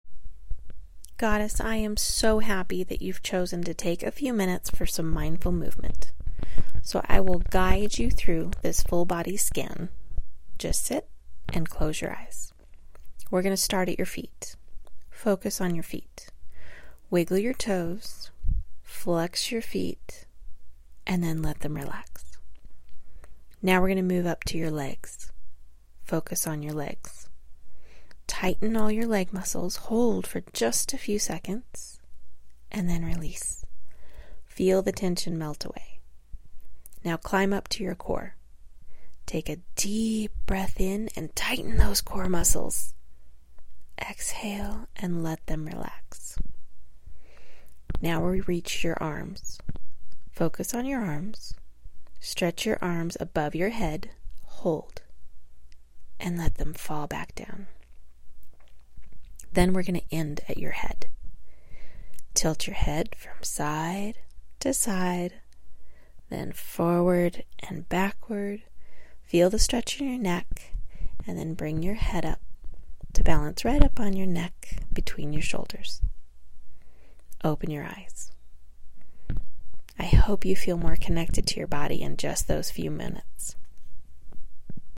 Guided Body Scan
Mindful-Body-Scan.mp3